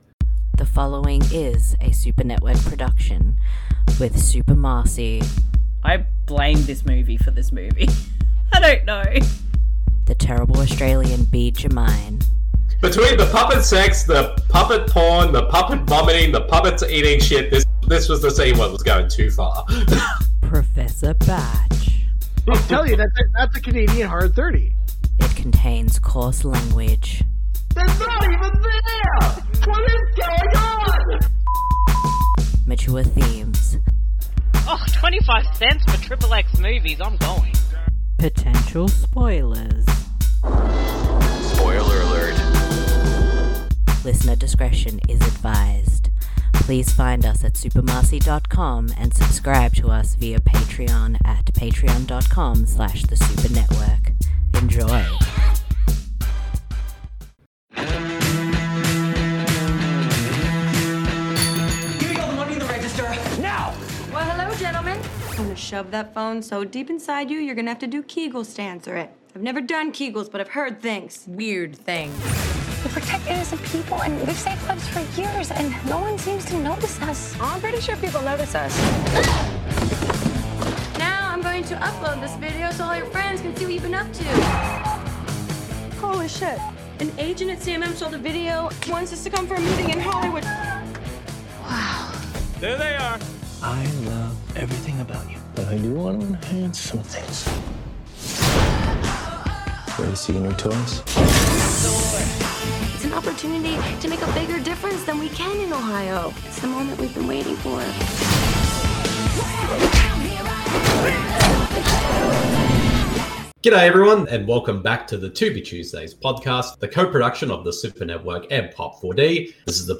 DISCLAIMER: This audio commentary isn’t meant to be taken seriously, it is just a humourous look at a film.